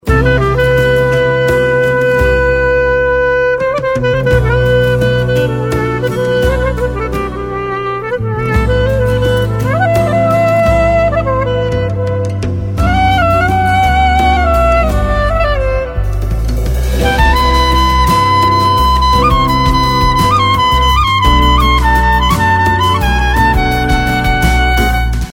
رینگتون محزون و بی کلام